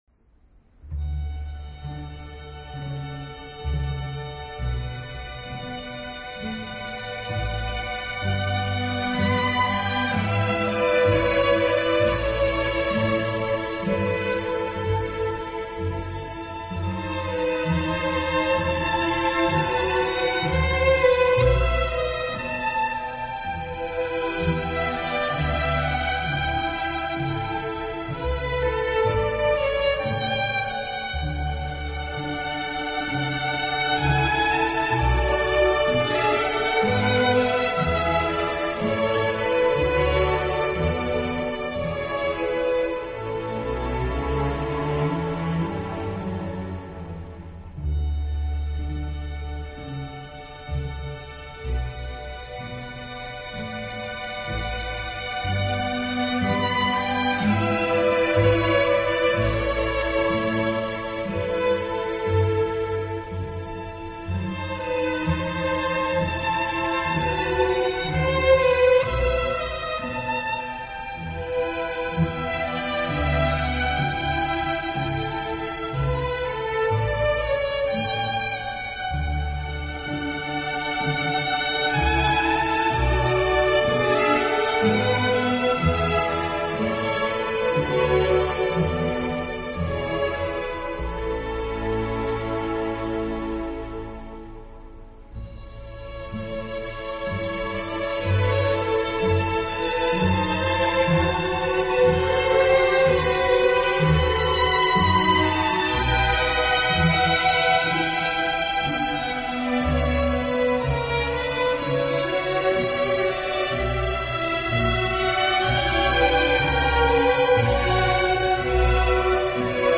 小提琴专辑
音乐类型：Classical